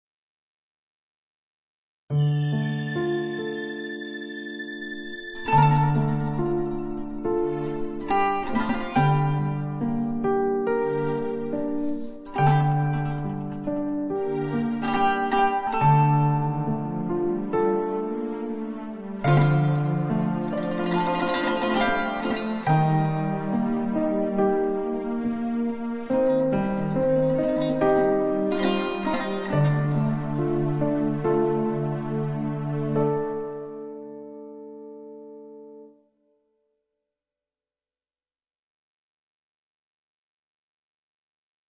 نمایش ازخاک تا افلاک - افکت صوتی